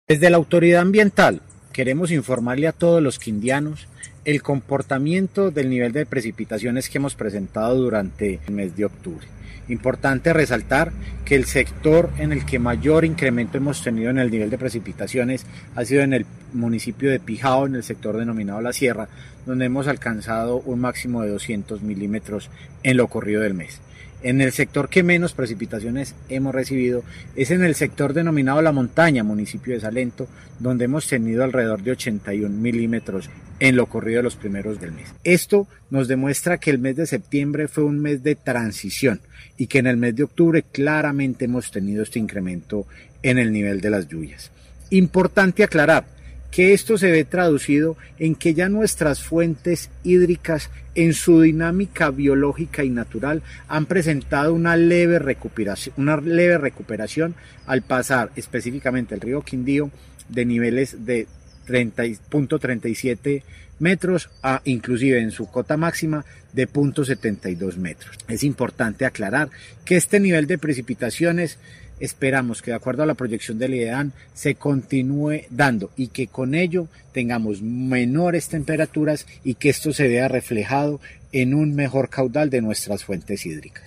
Director encargado de la CRQ, Juan Esteban Cortés